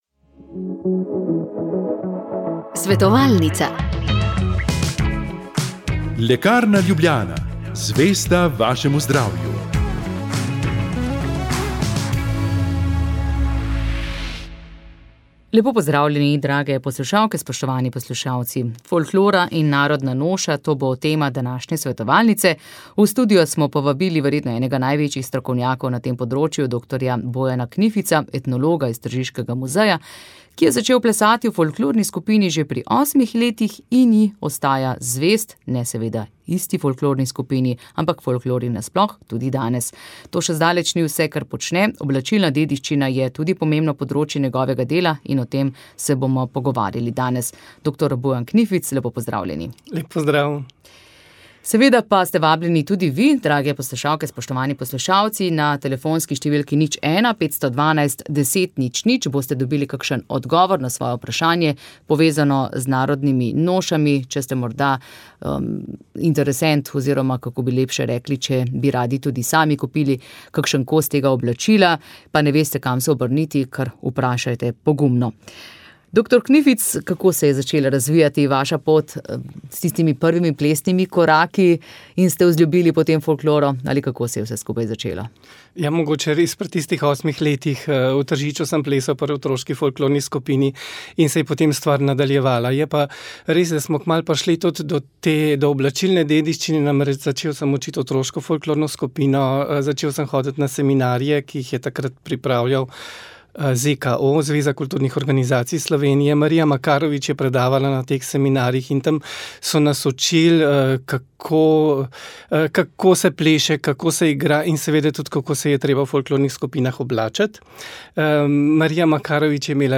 V studiu smo gostili enega največjih strokovnjakov na tem področju